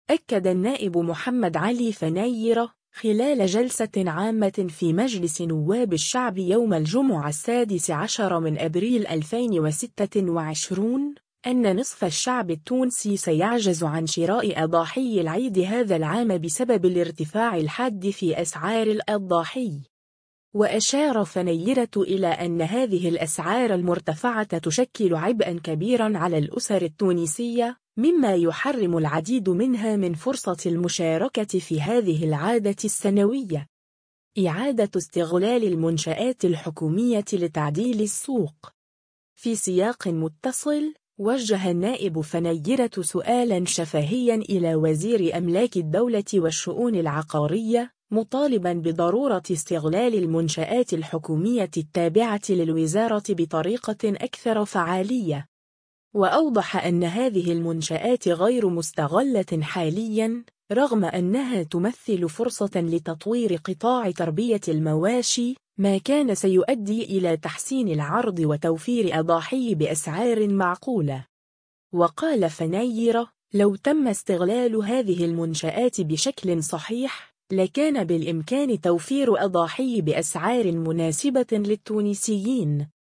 أكد النائب محمد علي فنيرة، خلال جلسة عامة في مجلس نواب الشعب يوم الجمعة 16 أبريل 2026، أن نصف الشعب التونسي سيعجز عن شراء أضاحي العيد هذا العام بسبب الارتفاع الحاد في أسعار الأضاحي.